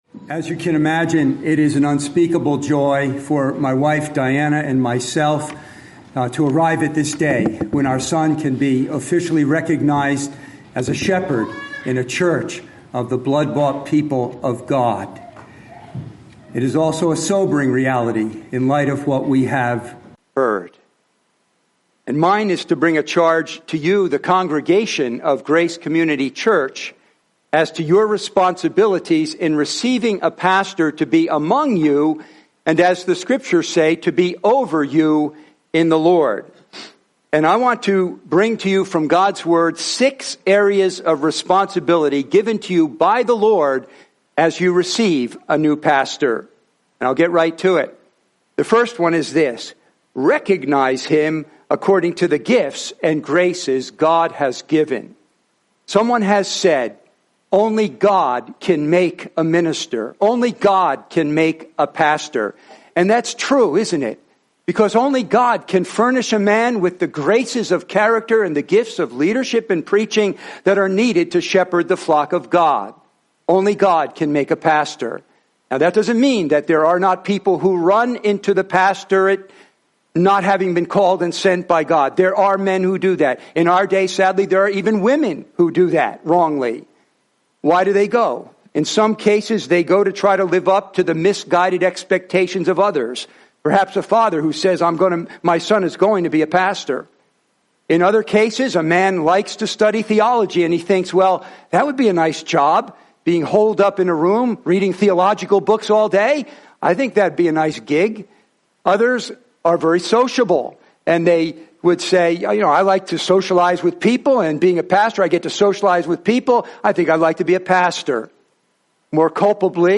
Preached at the ordination